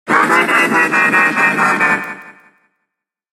evil_rick_lead_vo_01.ogg